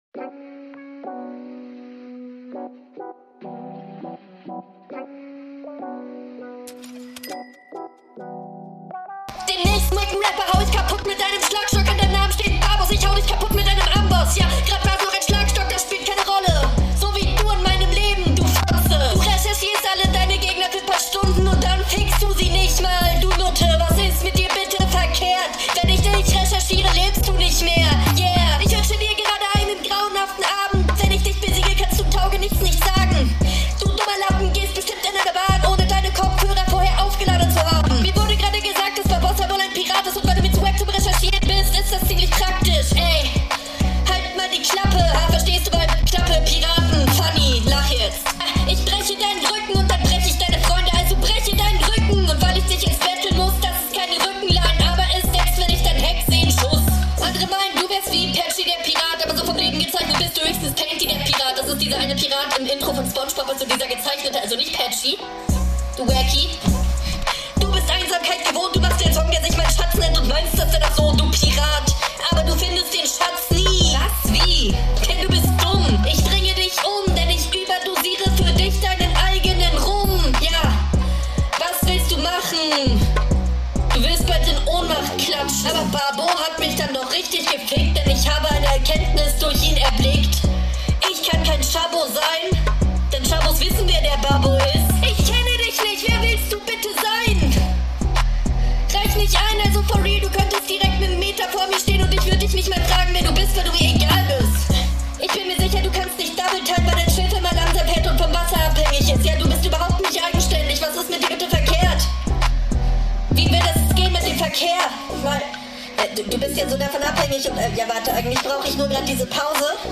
Grauenhafte Audioqualität, das tut wirklich weh beim anhören.